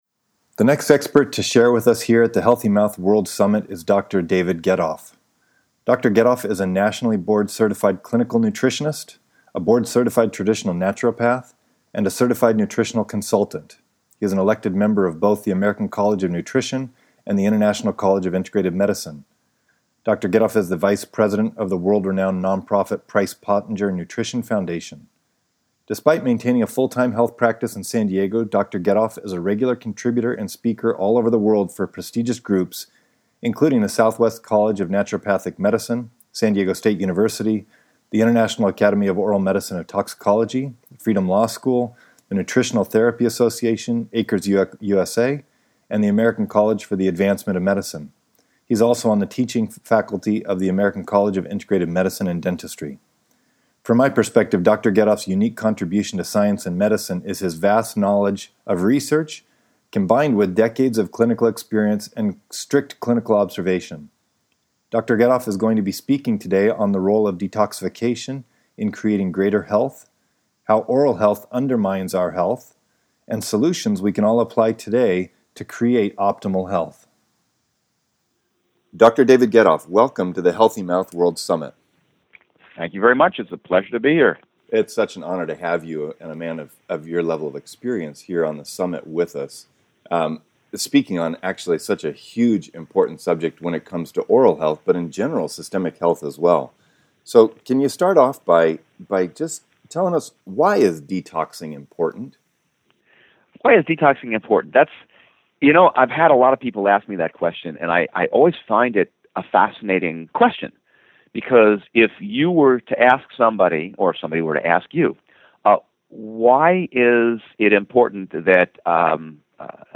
Expert Interview: The Role of Detox in Creating Greater Oral (and Whol